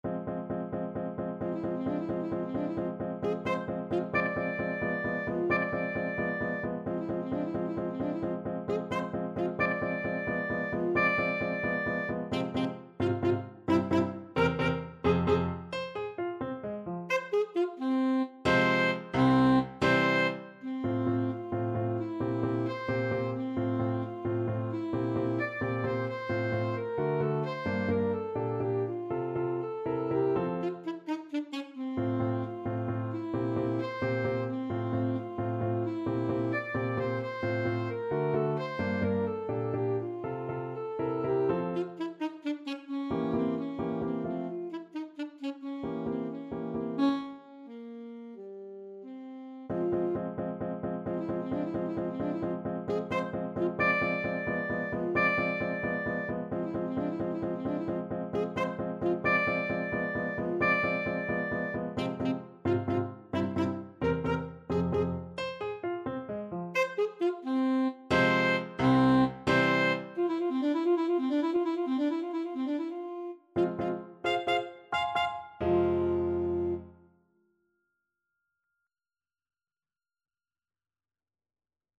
Ballade from 25 Progressive Pieces Alto Saxophone version
Alto Saxophone
F minor (Sounding Pitch) D minor (Alto Saxophone in Eb) (View more F minor Music for Saxophone )
Allegro con brio (.=104) .=88 (View more music marked Allegro)
G4-F6
3/8 (View more 3/8 Music)
Classical (View more Classical Saxophone Music)